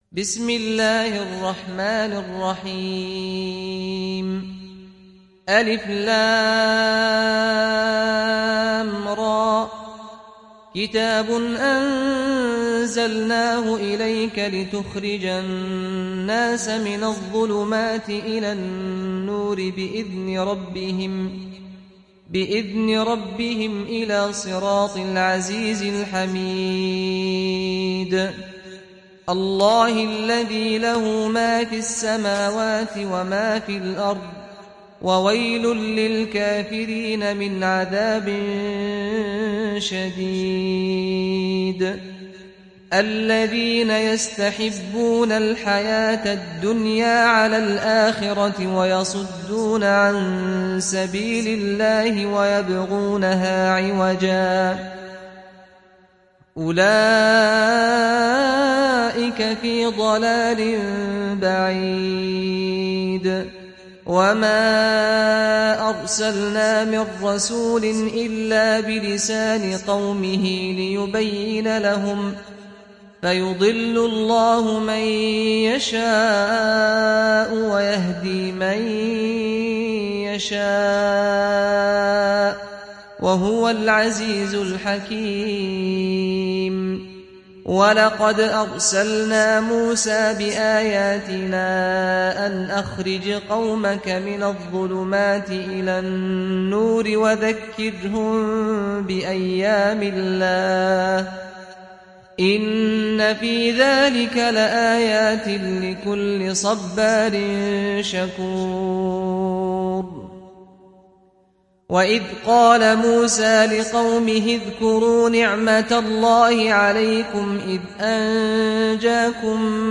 تحميل سورة إبراهيم mp3 بصوت سعد الغامدي برواية حفص عن عاصم, تحميل استماع القرآن الكريم على الجوال mp3 كاملا بروابط مباشرة وسريعة